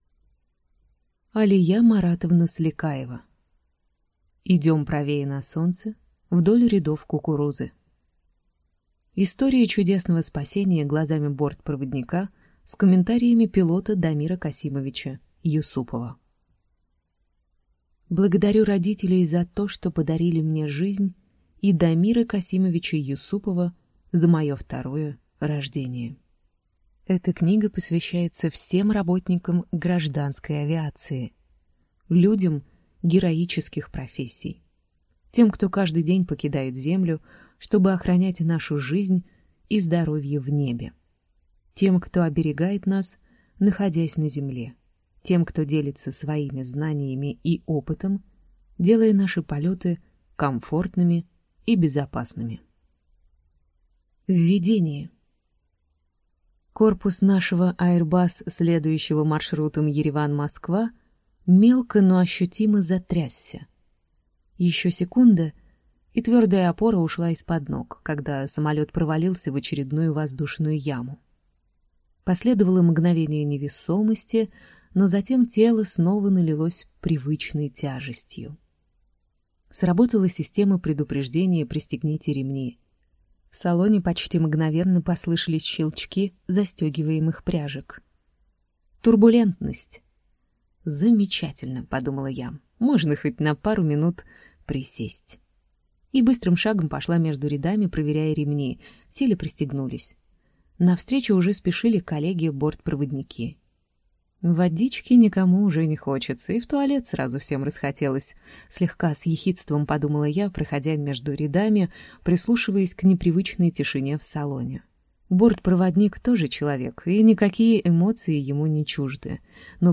Аудиокнига Идем правее, на солнце, вдоль рядов кукурузы. История чудесного спасения глазами бортпроводника | Библиотека аудиокниг